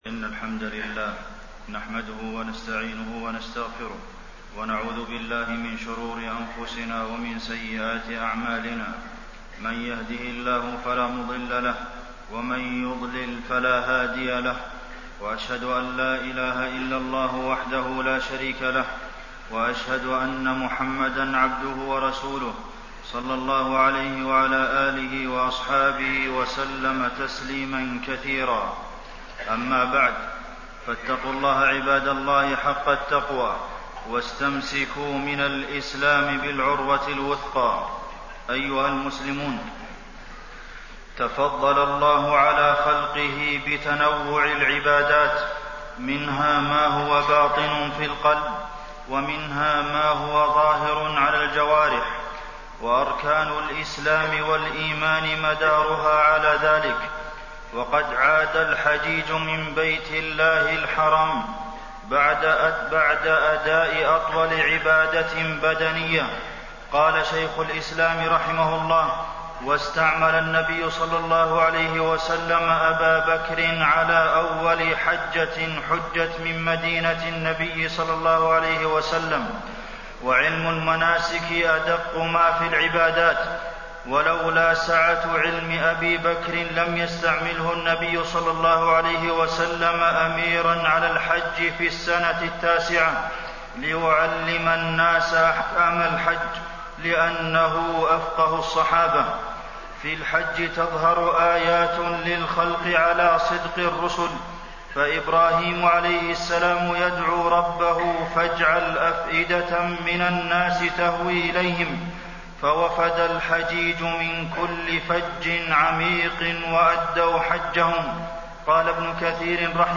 تاريخ النشر ٢٢ ذو الحجة ١٤٣٢ هـ المكان: المسجد النبوي الشيخ: فضيلة الشيخ د. عبدالمحسن بن محمد القاسم فضيلة الشيخ د. عبدالمحسن بن محمد القاسم آيات الله في الحج